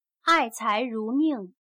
爱才如命/Ài cái rú mìng/consecuentemente a su capacidad se cumplirán sus instrucciones.